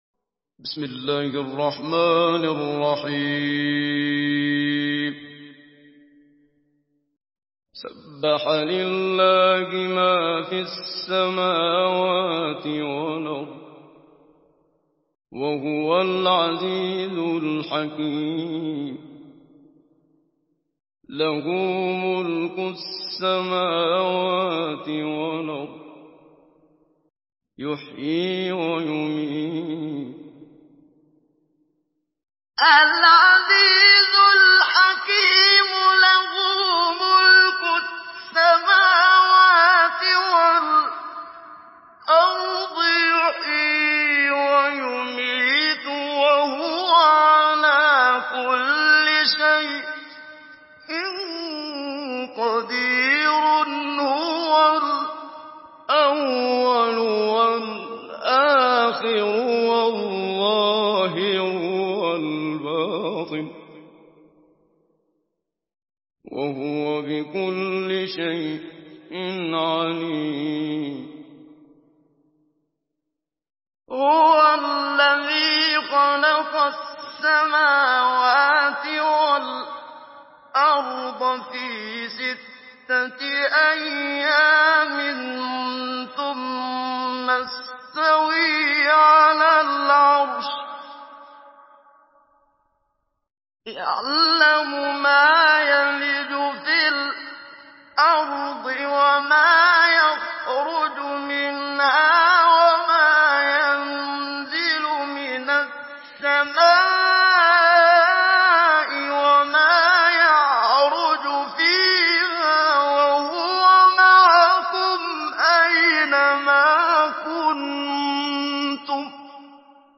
سورة الحديد MP3 بصوت محمد صديق المنشاوي مجود برواية حفص